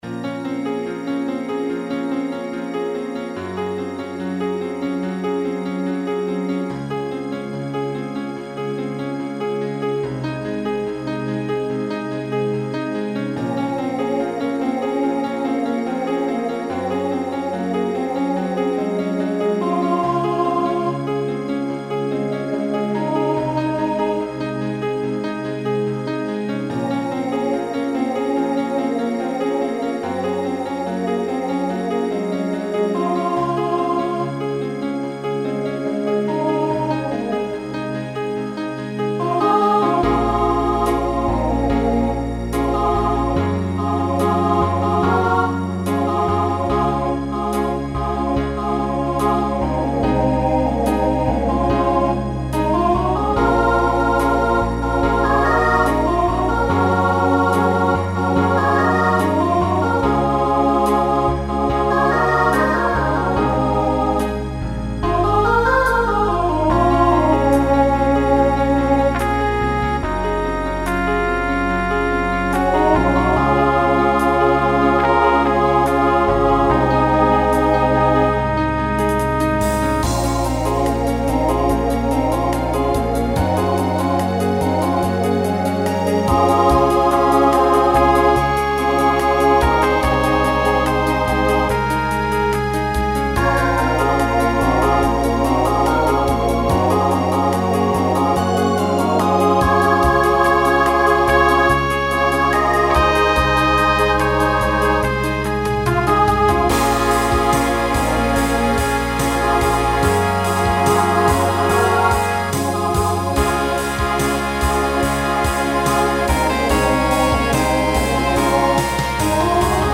Voicing SSA Instrumental combo Genre Pop/Dance
Function Ballad